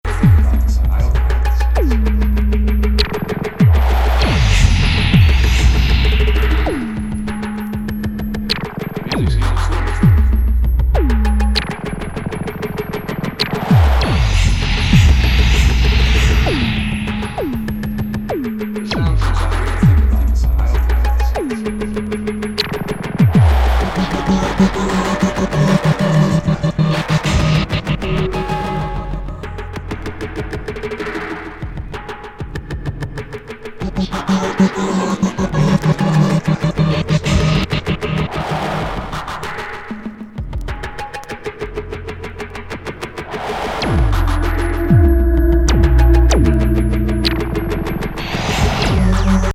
サイケデリックなノイズ～音響～電子音の世界。